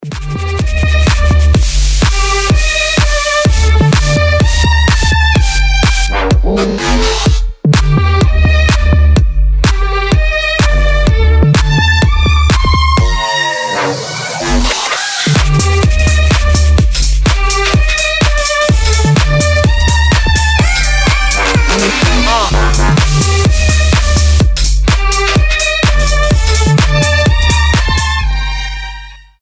• Качество: 320, Stereo
громкие
красивые
Electronic
EDM
future house
скрипка
Bass
Violin
Фьюче-хаус со скрипкой - звучит классно!